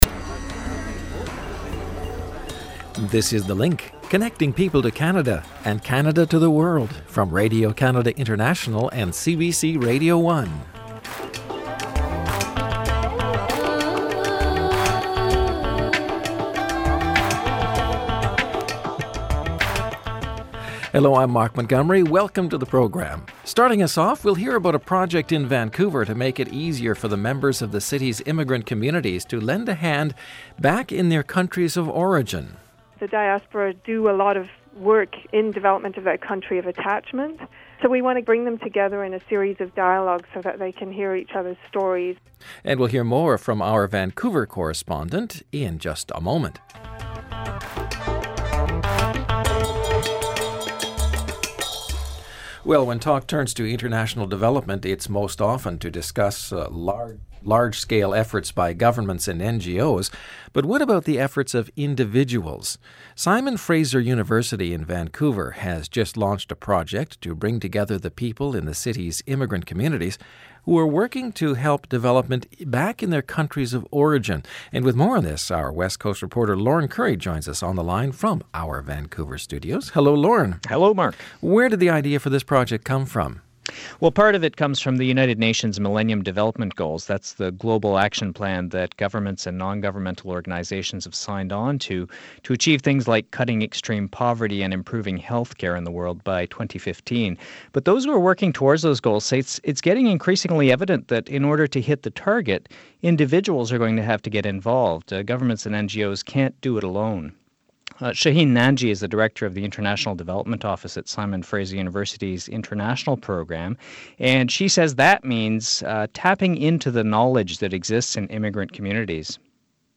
Radio Interview University project taps the knowledge of immigrant communities